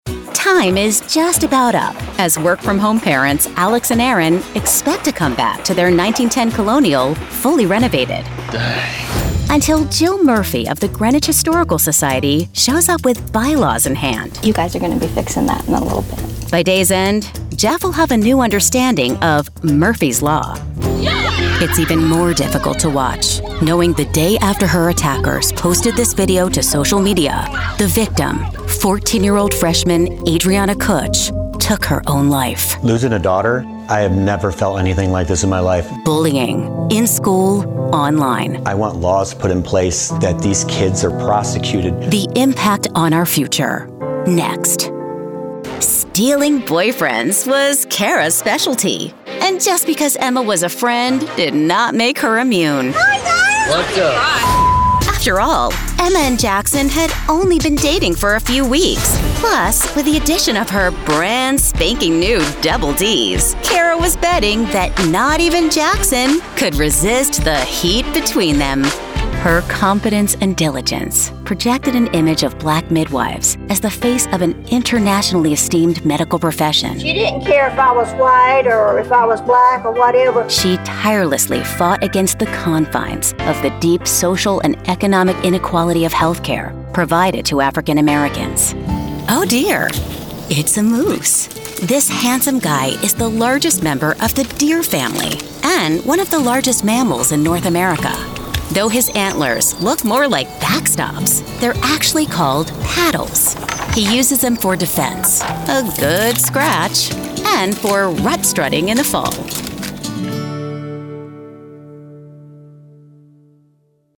Narration Showreel
Female
American Standard
Bright
Friendly
Warm